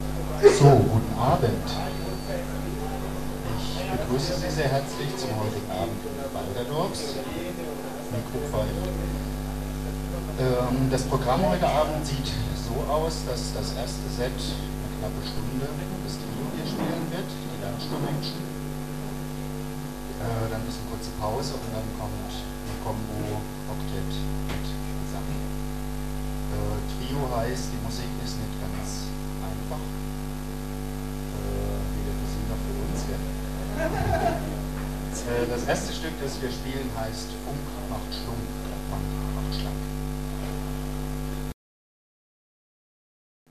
Ansage